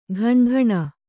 ♪ ghaṇaghaṇa